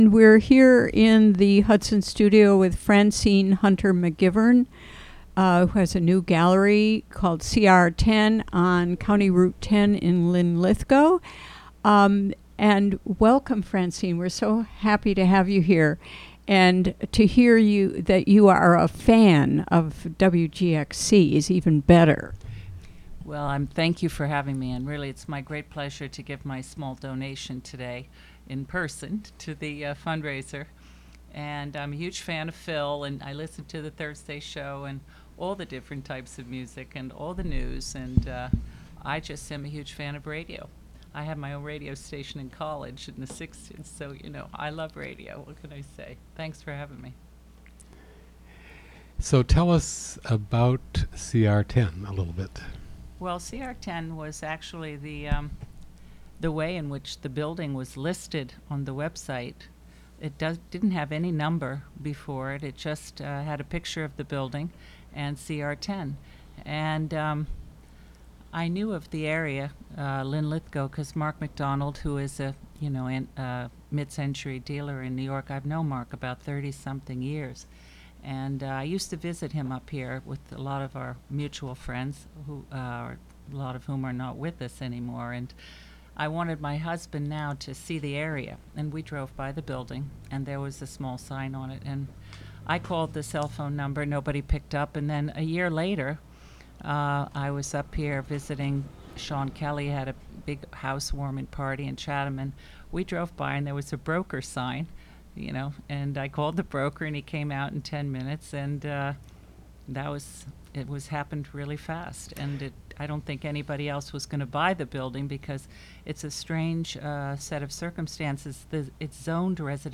Interview
WGXC Afternoon Show," from Hudson studio.